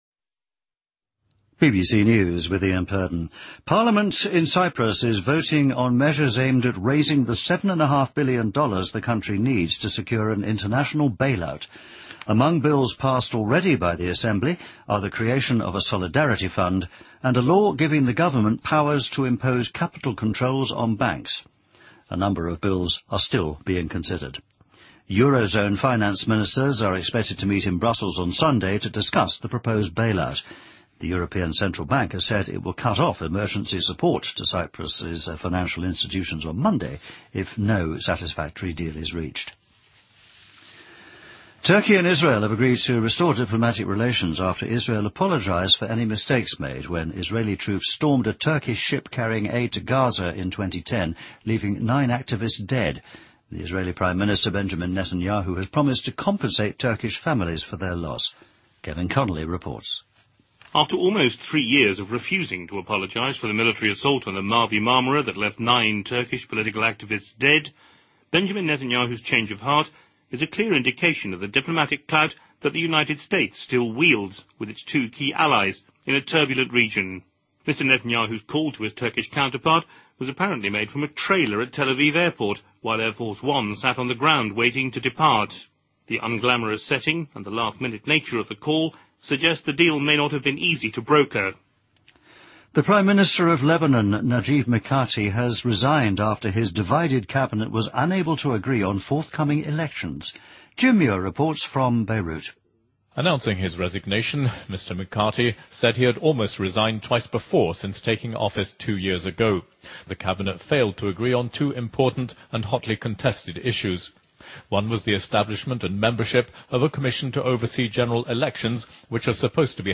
BBC news,2013-03-23